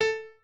pianoadrib1_47.ogg